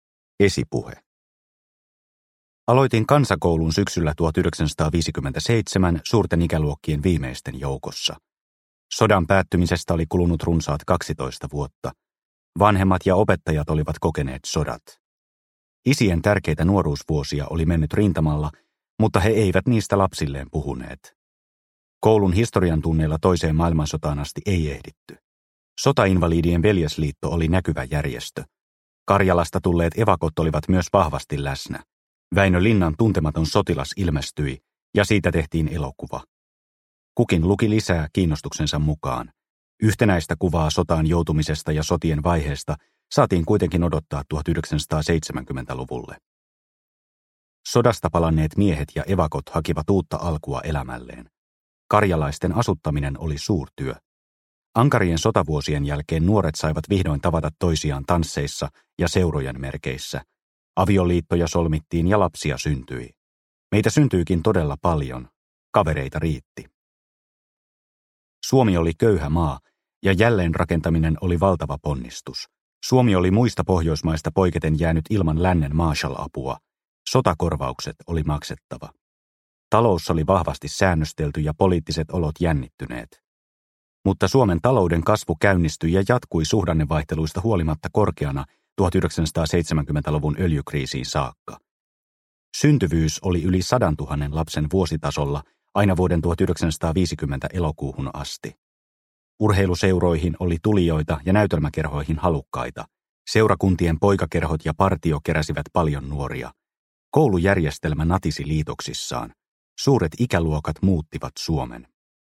Olin joukon nuorin – Ljudbok – Laddas ner